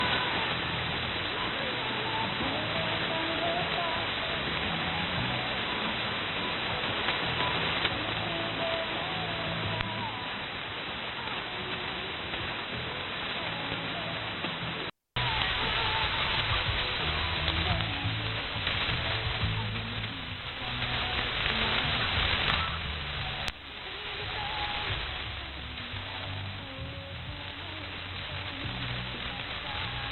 For the comparison I made audio recordings of the two receivers.
Second 0-15 >> SDRplay RSPduo
Second 15-30 >> Winradio G33DDC Excalibur Pro